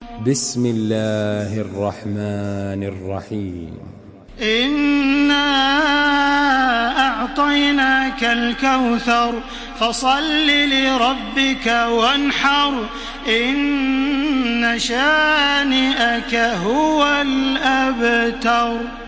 Surah الكوثر MP3 by تراويح الحرم المكي 1433 in حفص عن عاصم narration.
مرتل